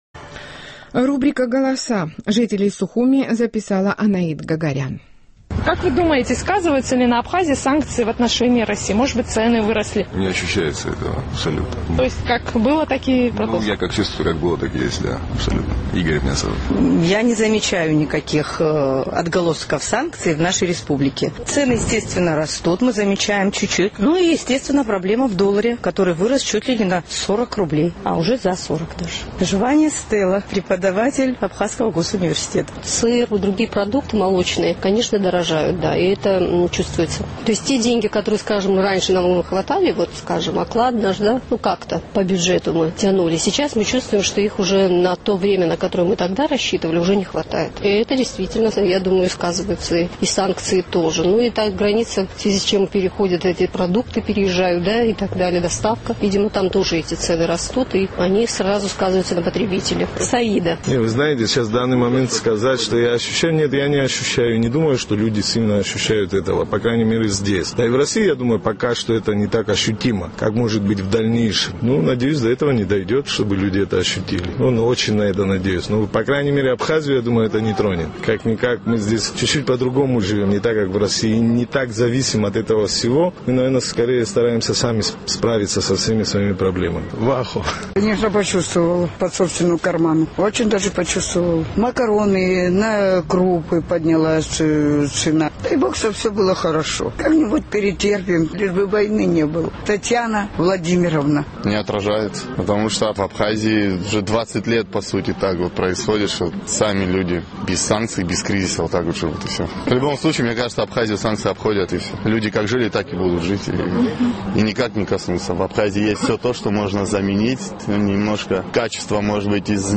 Наш сухумский корреспондент интересовался у жителей абхазской столицы, сказываются ли на республике санкции против России, и отразились ли они на ценах.